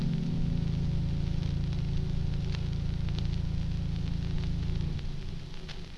Vinyl_Tone_Layer_06.wav